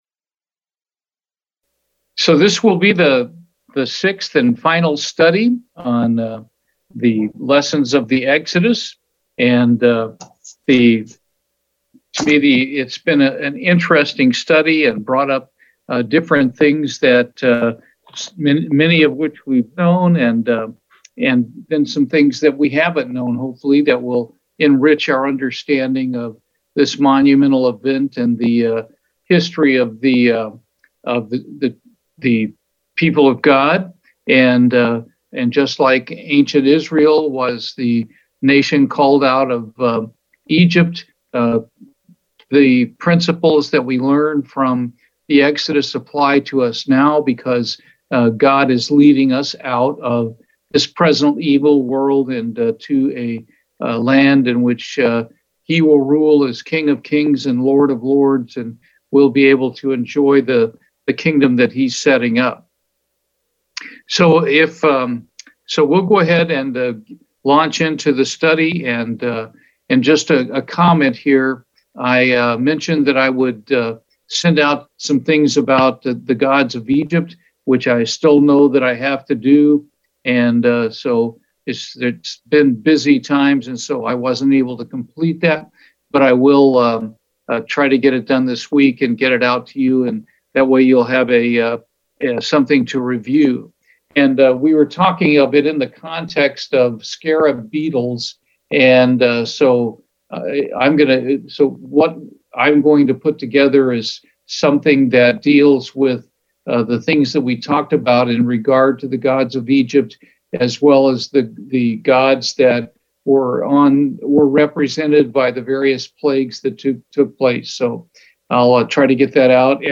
Sermons
Given in Houston, TX